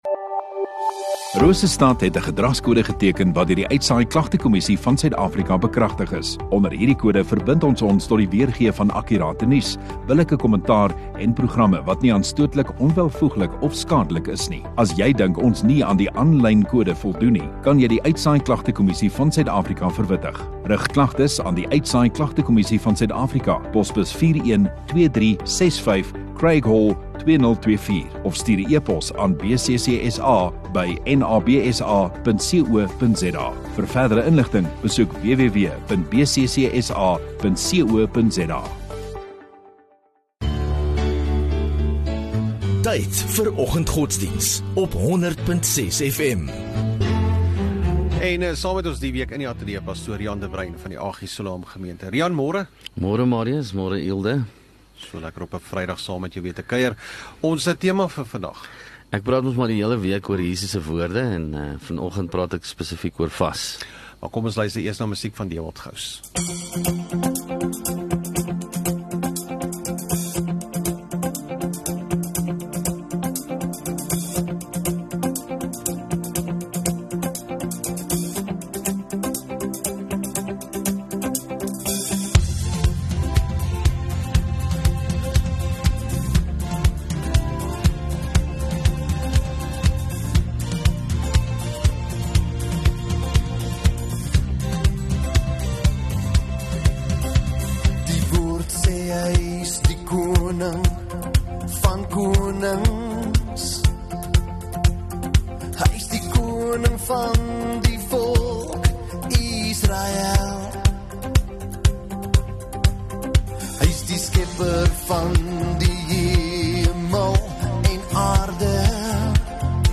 24 Jan Vrydag Oggenddiens